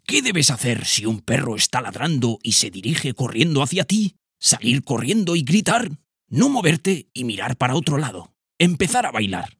TEST PERROS DESCONOCIDOS-Narrador-10_0.mp3